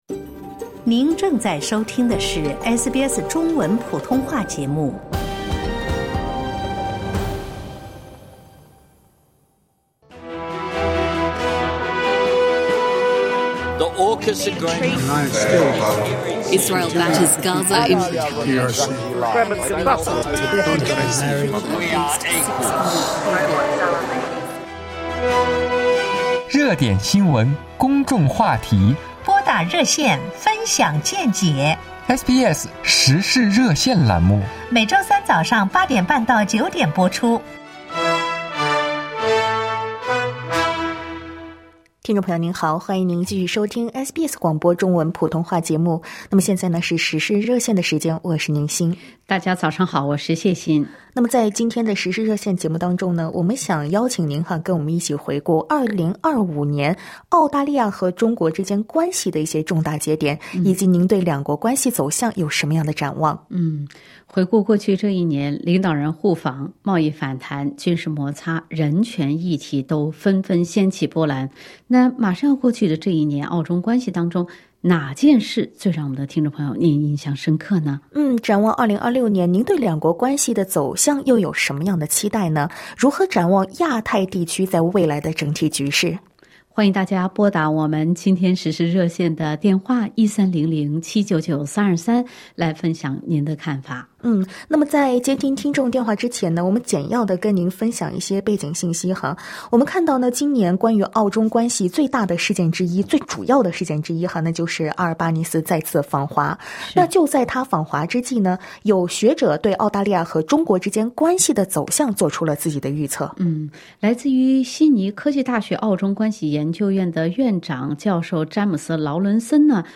在本期《时事热线》节目中，听友们对澳中关系展望发表了各自的看法。